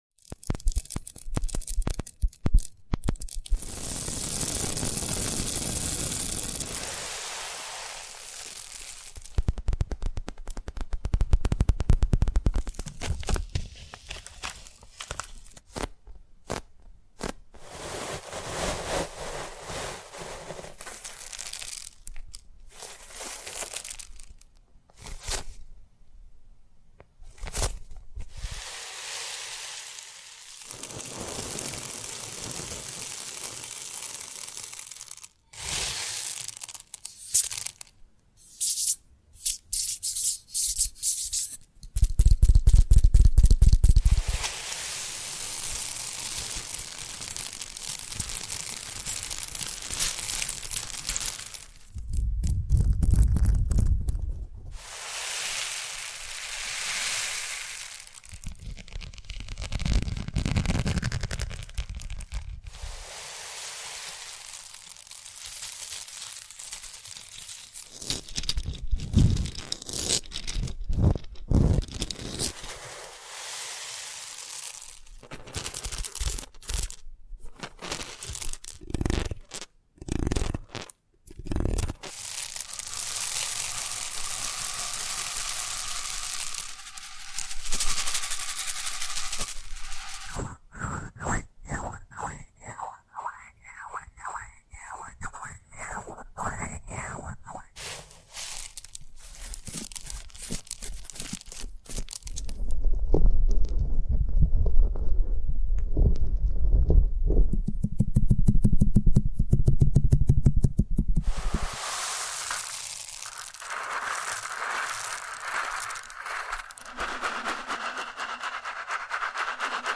ASMR Which Prop’s Your Favorite?